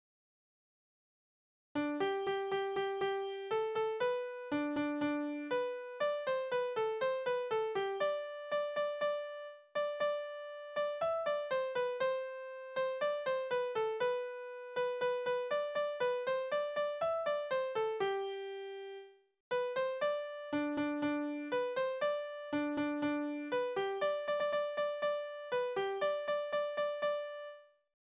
Tonart: G-Dur
Taktart: C (4/4)
Tonumfang: große None
Besetzung: vokal